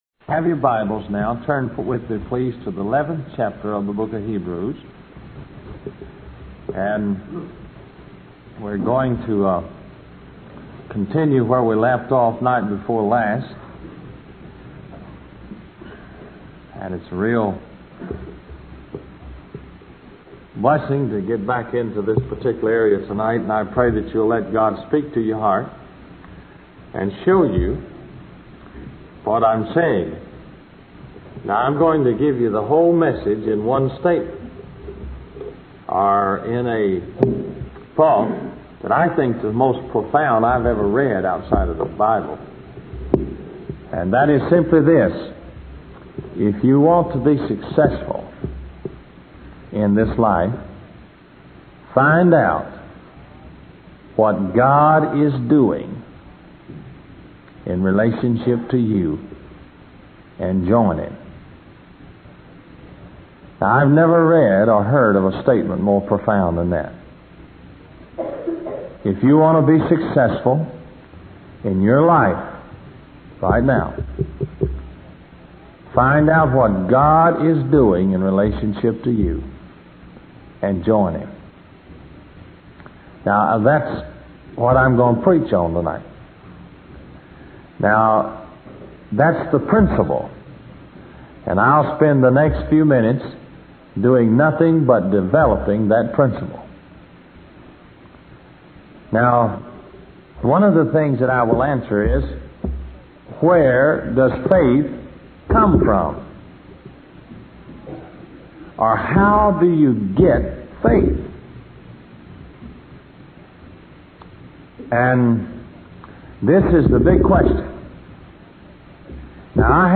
In this sermon, the preacher shares two stories from the Bible to emphasize the importance of seeking God's perspective in difficult situations.